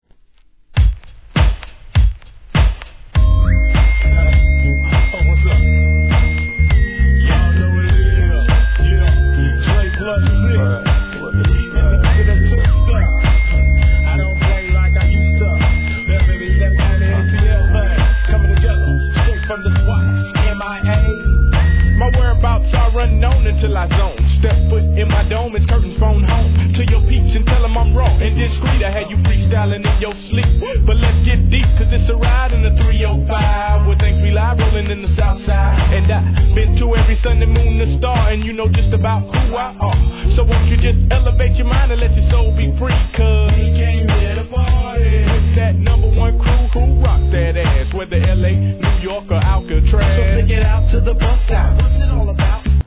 G-RAP/WEST COAST/SOUTH
シンセの爽快なメロ〜トラックに賑やかに盛り上げるフックも最高!!